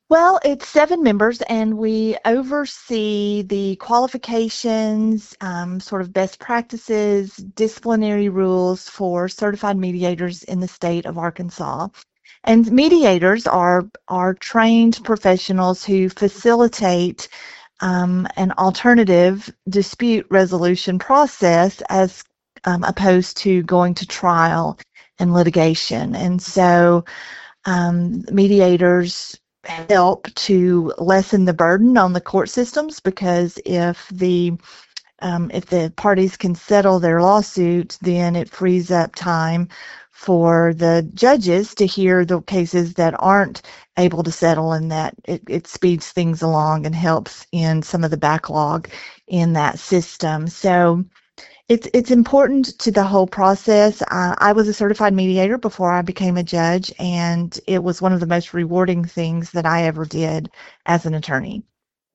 KTLO News spoke with Carney, who explains what the commission does.